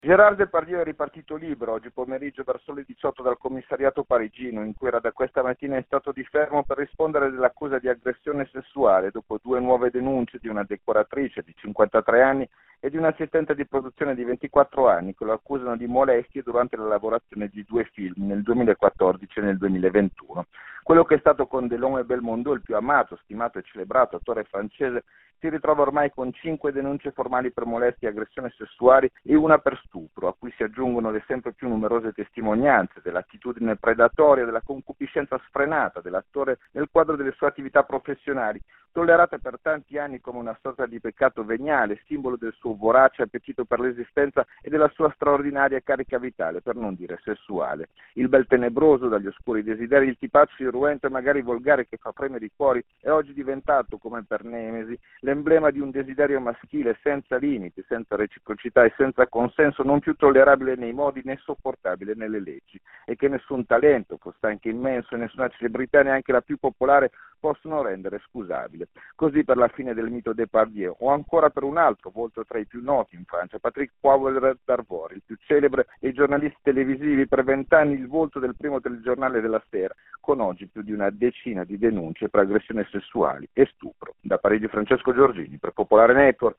Il racconto della giornata di lunedì 29 aprile 2024 con le notizie principali del giornale radio delle 19.30. L’attesa per la risposta di Hamas alla proposta di tregua presentata da Israele.